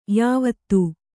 ♪ yāvattu